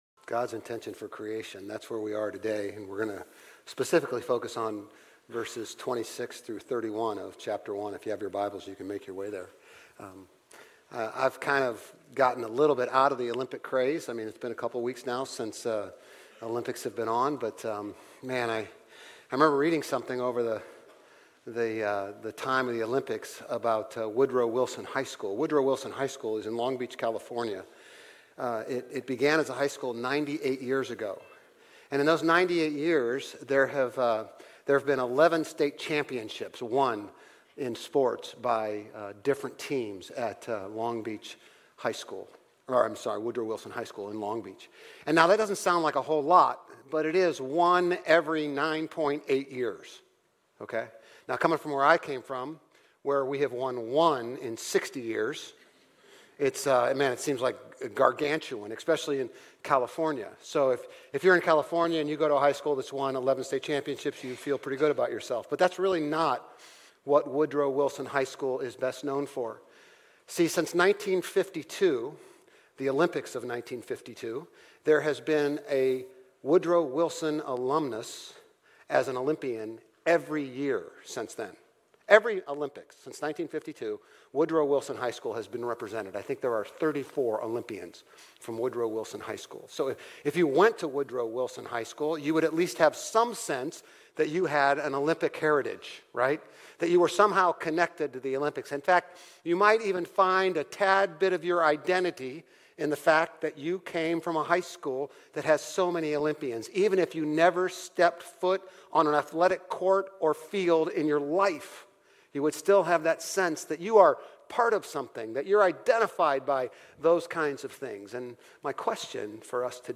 Grace Community Church Old Jacksonville Campus Sermons Genesis 1 - Image of God Aug 25 2024 | 00:31:06 1x 00:00 / 00:31:06 Subscribe Share RSS Feed Share Link Embed